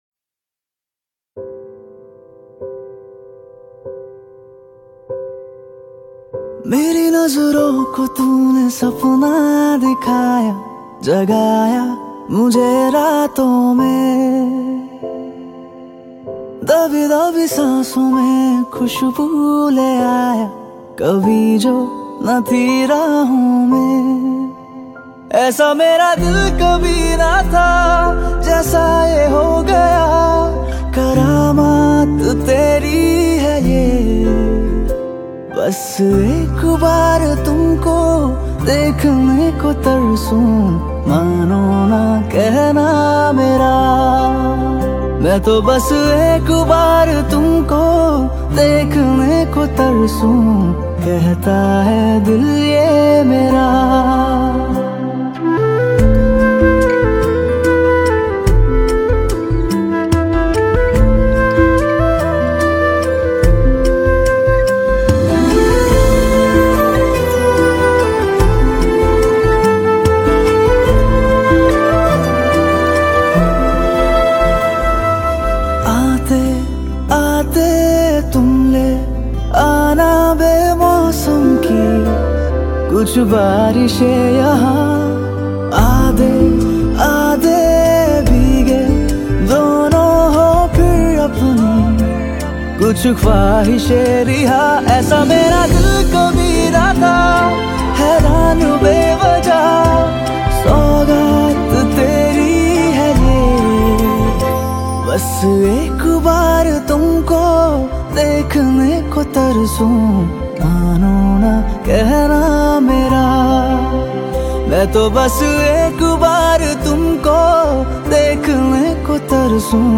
Pop Songs
Indian Pop